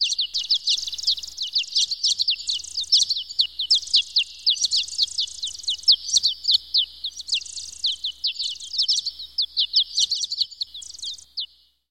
SFX鸟叫音效下载
这是一个免费素材，欢迎下载；音效素材为鸟叫音效， 格式为 wav，大小3 MB，源文件无水印干扰，欢迎使用国外素材网。